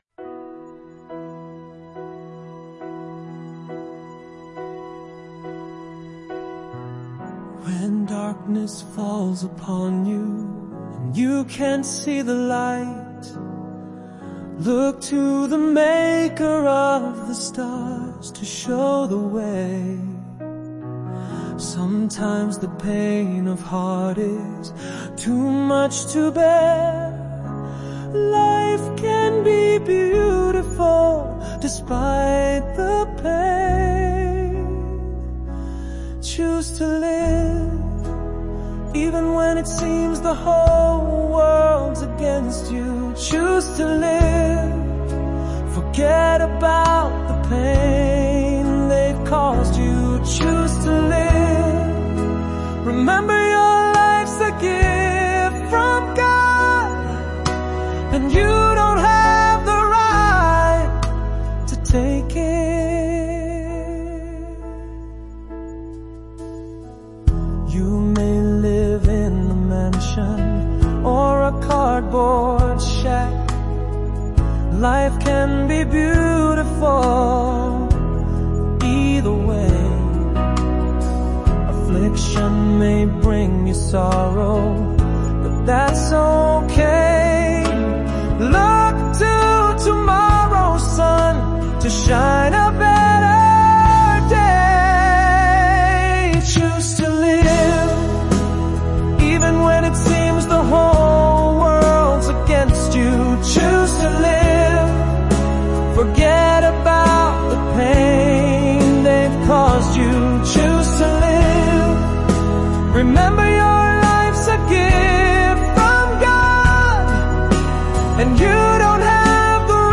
Revised July 28 with music and vocals